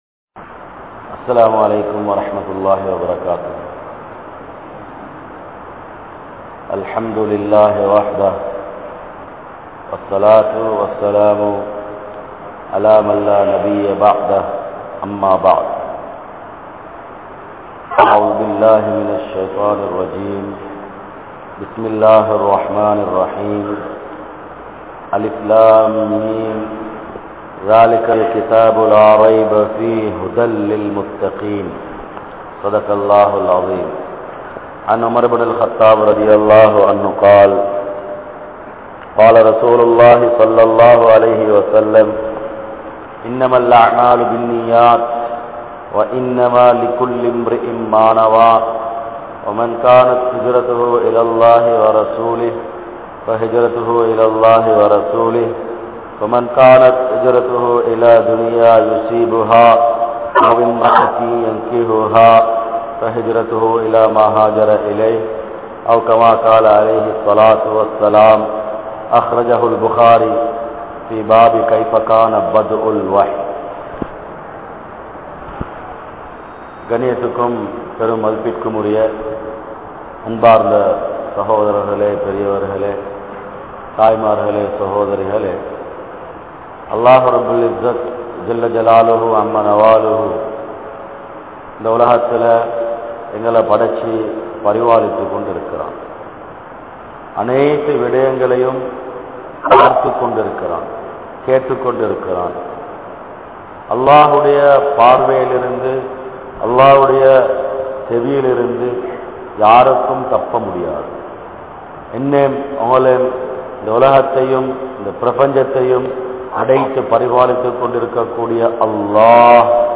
Paavaththin Vilaivuhal (பாவத்தின் விளைவுகள்) | Audio Bayans | All Ceylon Muslim Youth Community | Addalaichenai